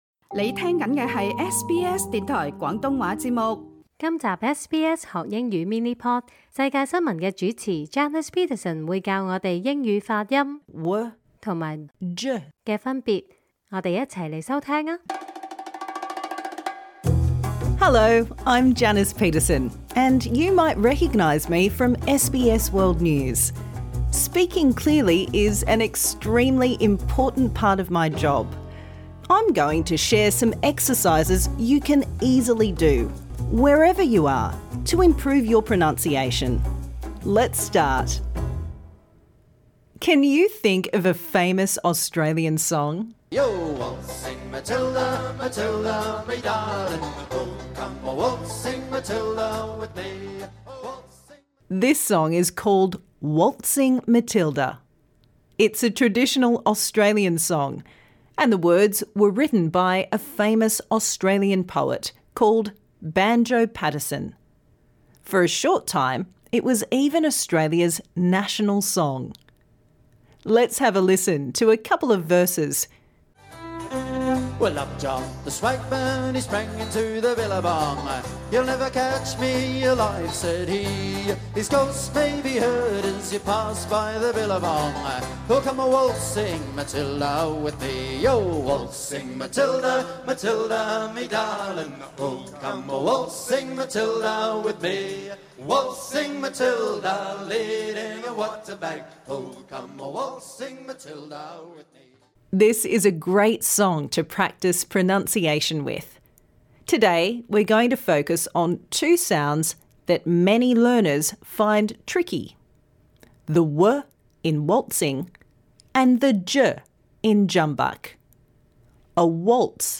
Minimal Pairs : /w/ war weep wet - /ʤ/ jaw jeep jet SBS Learn English will help you speak, understand and connect in Australia. This lesson suits all learners at all levels.